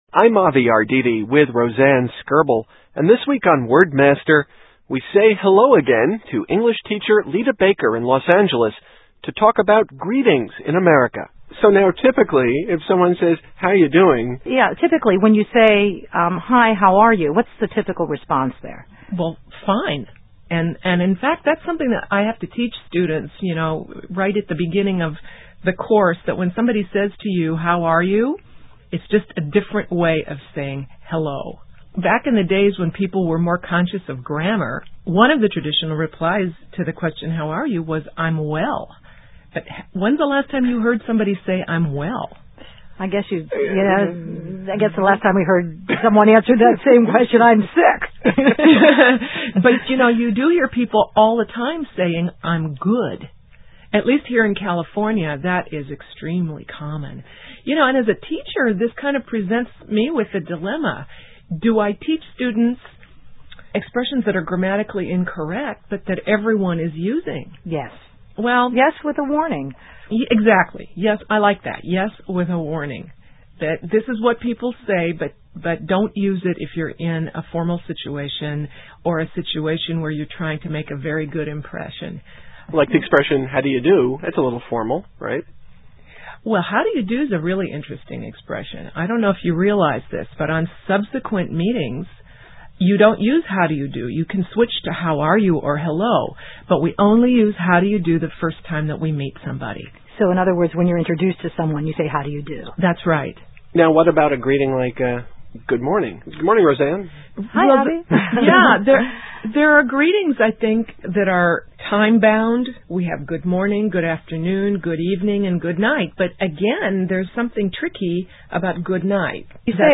Broadcast: February 22, 2005